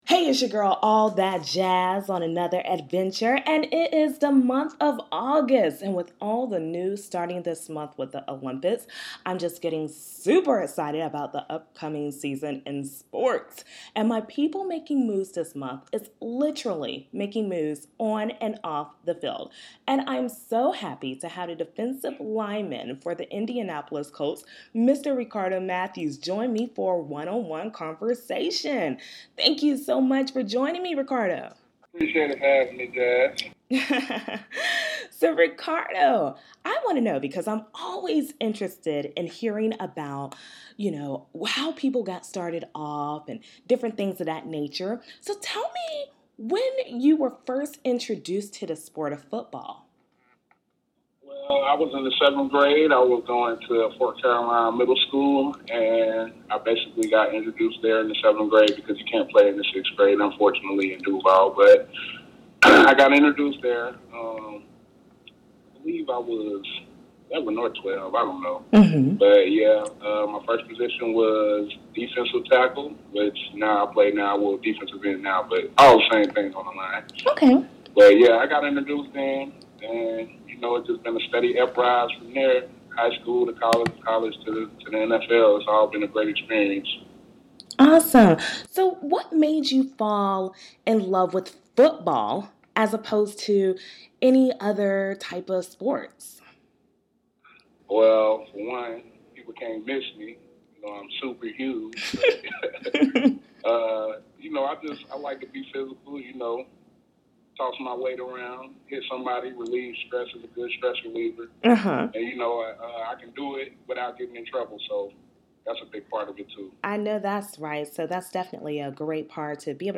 A One on One Conversation with Ricardo Mathews